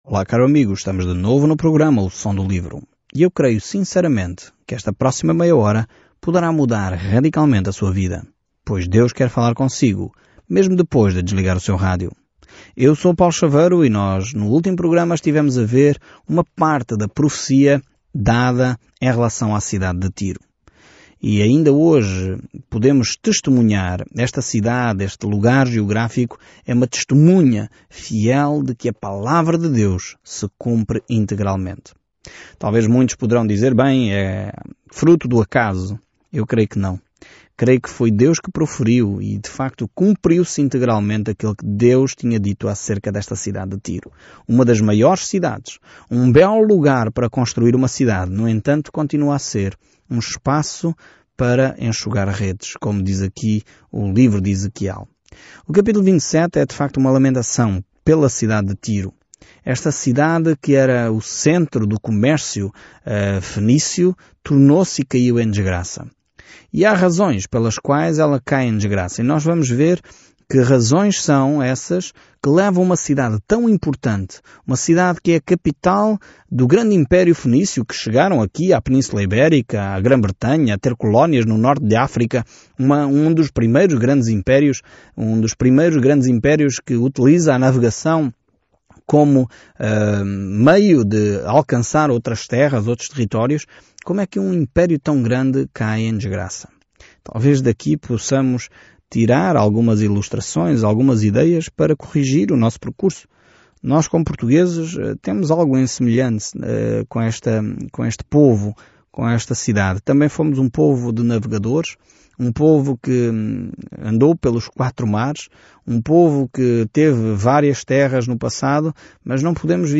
Scripture Ezekiel 27 Ezekiel 28 Day 15 Start this Plan Day 17 About this Plan O povo não quis ouvir as palavras de advertência de Ezequiel para retornar a Deus, então, em vez disso, ele encenou as parábolas apocalípticas, e isso perfurou o coração das pessoas. Viaje diariamente por Ezequiel enquanto ouve o estudo em áudio e lê versículos selecionados da palavra de Deus.